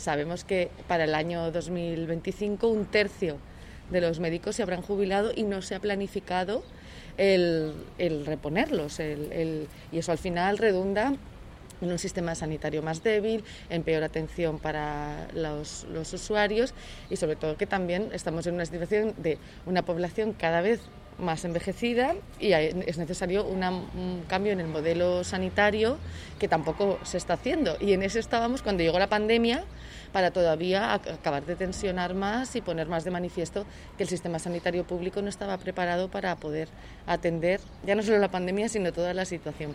Les declaracions de la diputada, acompanyada dels membres del grup municipal de la formació taronja a la ciutat, es van realitzar davant l’ambulatori de Les Fontetes perquè, indica Navarro, que estigui tancat i que les urgències s’hagin de fer al CUAP constaten el trasllat que ha de fer la ciutadania d’un barri tant poblat com el de Les Fontetes per rebre atenció mèdica.
Declaracions de Blanca Navarro: